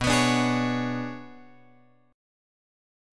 B Minor 6th Add 9th